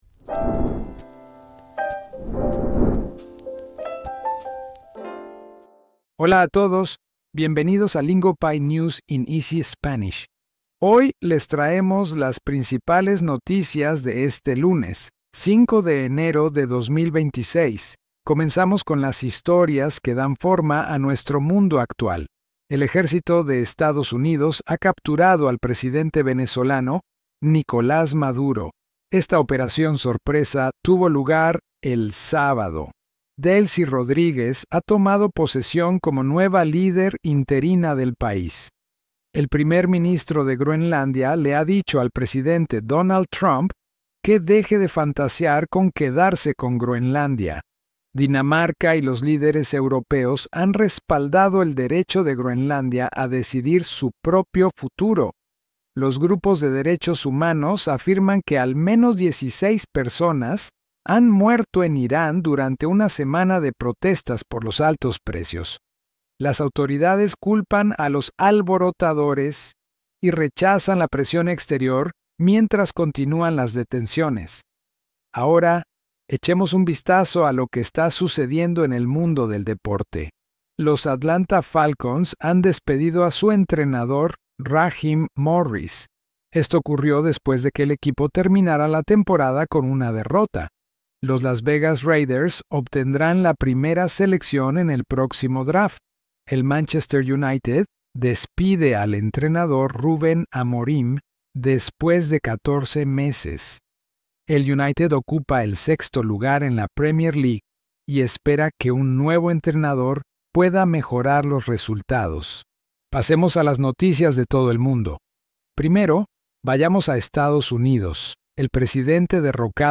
Think your Spanish isn’t good enough for the news? Lingopie’s News in Easy Spanish proves it is, with today’s biggest stories told in clear, structured Spanish.